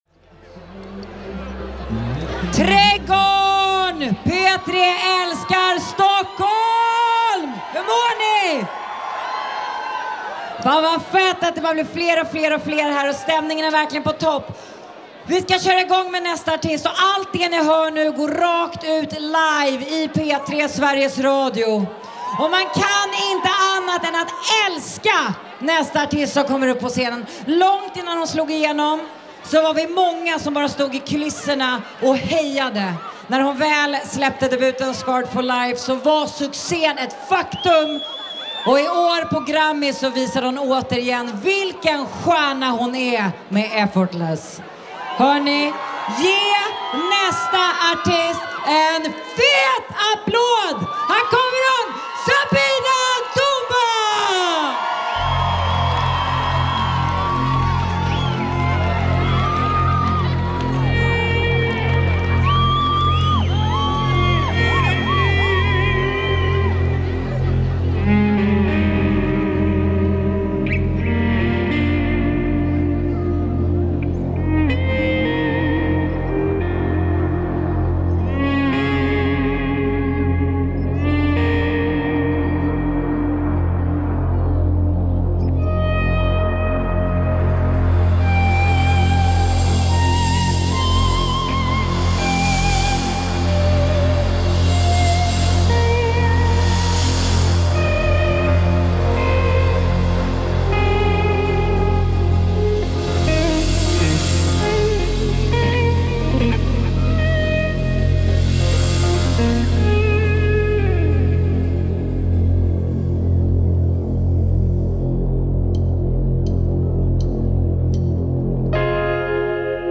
Swedish Soul
with her band and to an enthusiastic audience.
Take a break and check out a taste of Svenska Soul tonight.